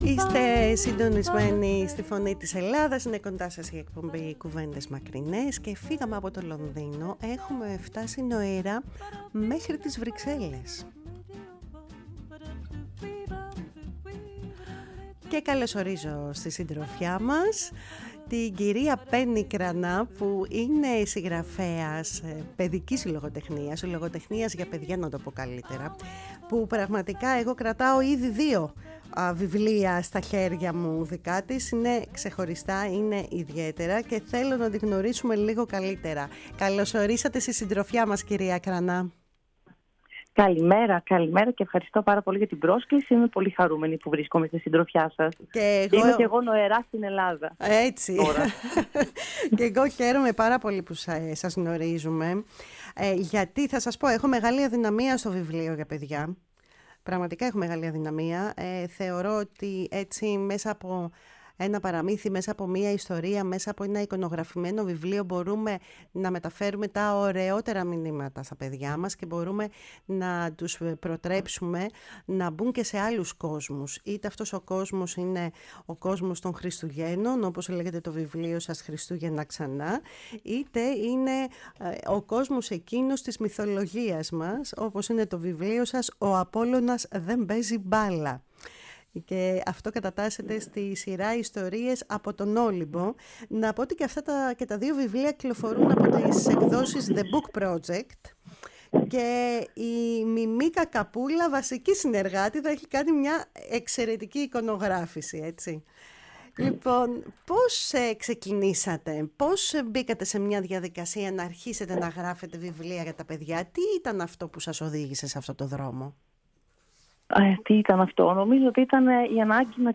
Η ΦΩΝΗ ΤΗΣ ΕΛΛΑΔΑΣ Κουβεντες Μακρινες ΣΥΝΕΝΤΕΥΞΕΙΣ Συνεντεύξεις